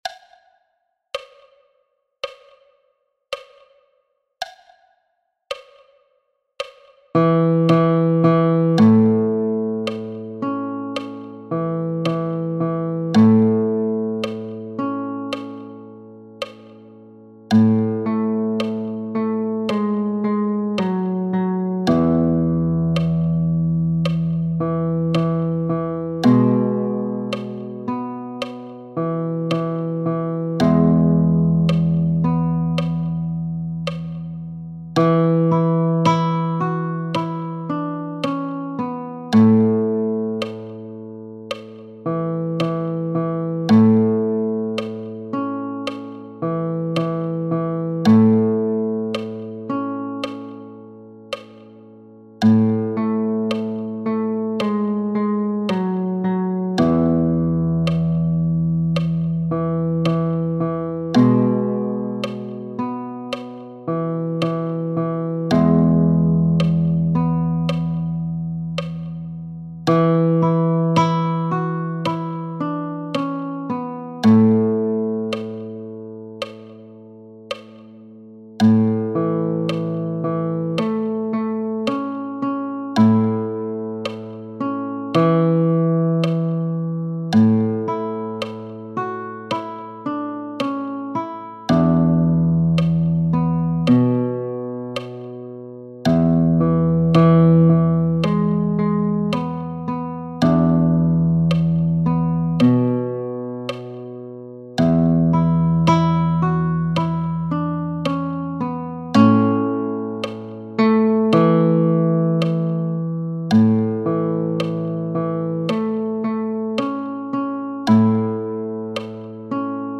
Der Song ist in einem langsamen Tempo aufgenommen. Die Instrumentierung wurde so gewählt dass sich der Klang möglichst wenig mit dem des eigenen Instrumentes vermischt.